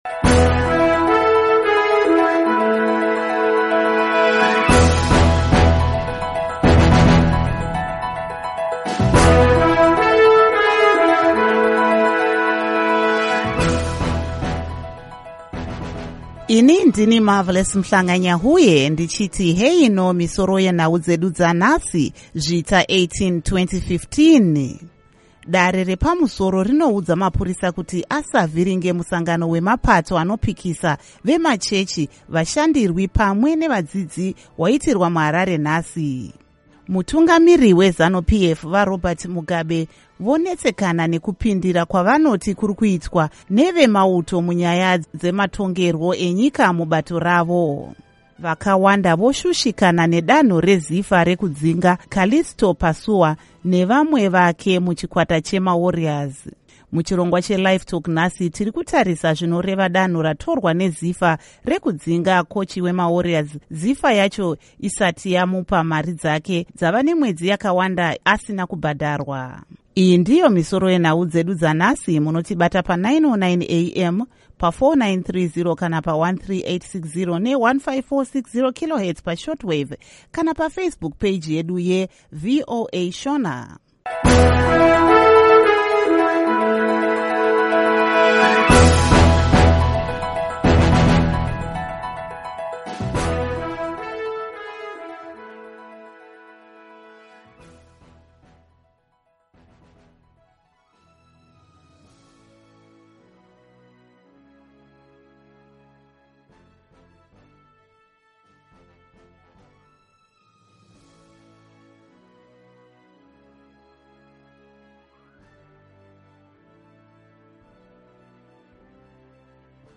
Misoro Yenhau Dzanhasi…Chishanu, Zvita, 18, 2015.